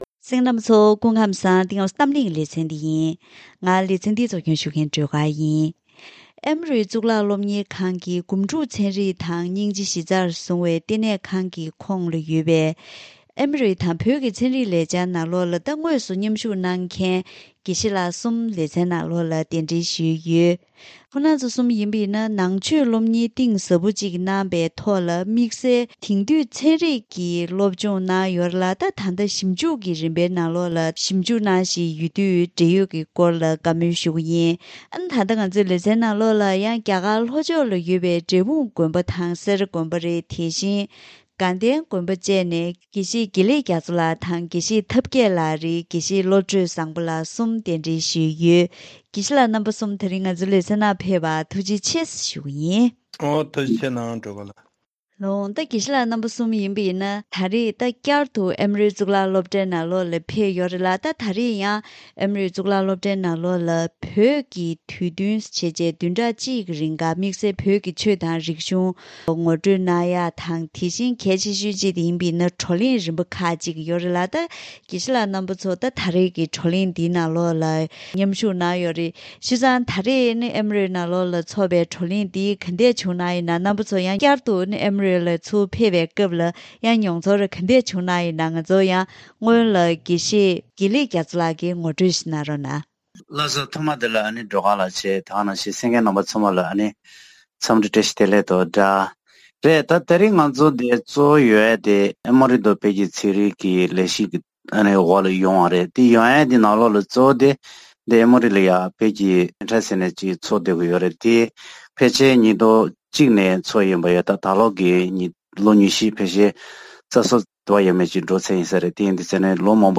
ད་རིང་གི་གཏམ་གླེང་ལེ་ཚན་ནང་། དཔྱད་སྒོམ་དང་ཐུགས་དམ། དྲན་པ་ཉམས་པ་སོགས་ཀྱི་ཐོག་ནང་པའི་ཚན་རིག་དང་དེང་དུས་རིག་གསར་གྱི་ཚན་རིག་ཟུང་འབྲེལ་ཐོག་ཉམས་ཞིབ་གནང་མཁན་དགེ་བཤེས་གསུམ་དང་ལྷན་དུ་ཚན་རིག་ཞིབ་འཇུག་གི་སྐོར་ལ་བཀའ་མོལ་ཞུས་པ་ཞིག་གསན་རོགས་གནང་།